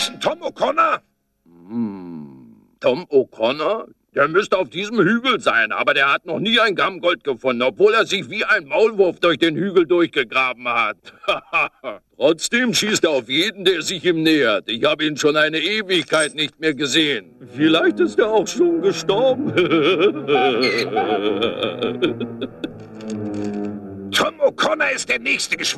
Goldgräber     -
luke-goldgraeber.mp3